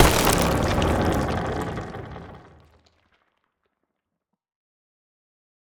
creaking_death.ogg